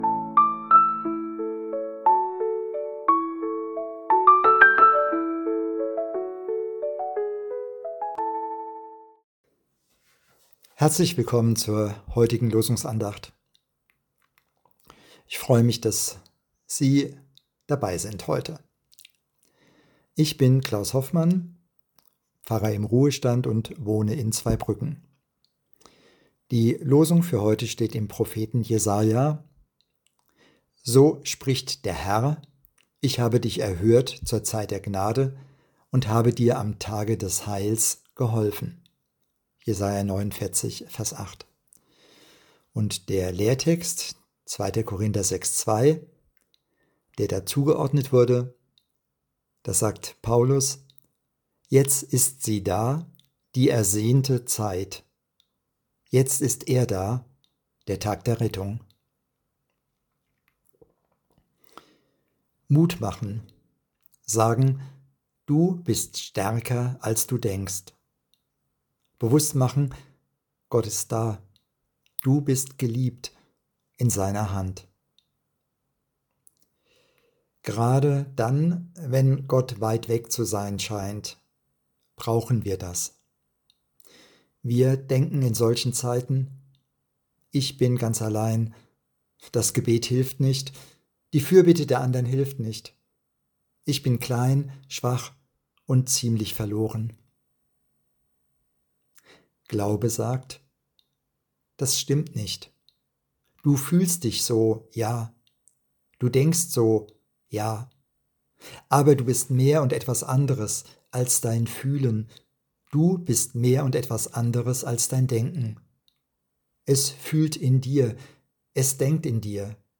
Losungsandacht für Samstag, 12.07.2025